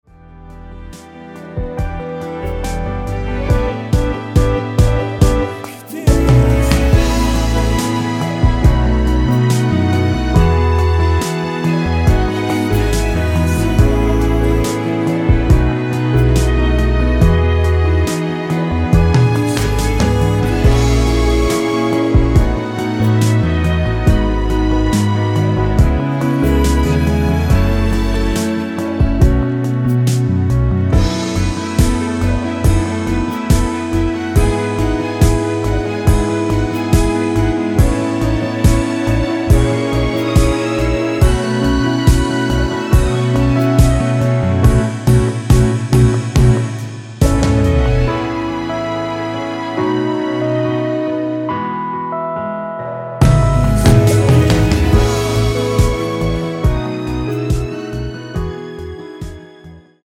원키 코러스 포함된 MR입니다.
앞부분30초, 뒷부분30초씩 편집해서 올려 드리고 있습니다.
중간에 음이 끈어지고 다시 나오는 이유는